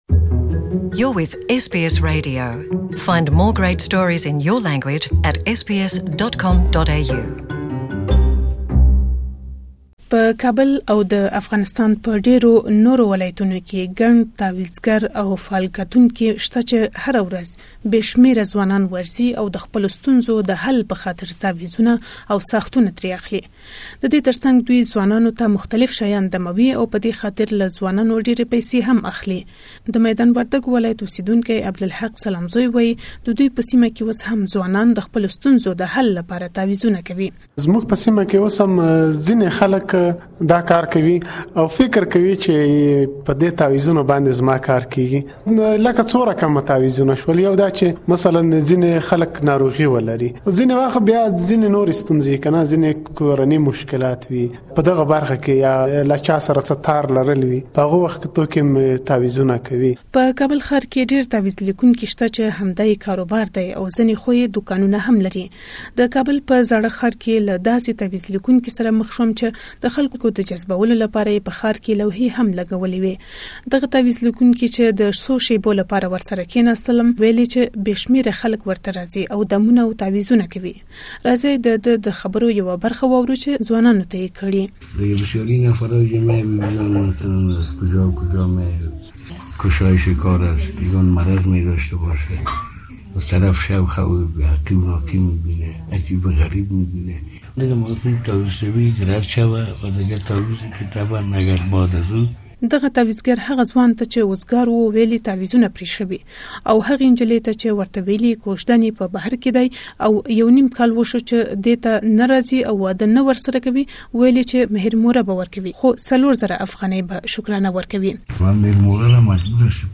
We have looked at this issue in Kabul city and got many voices.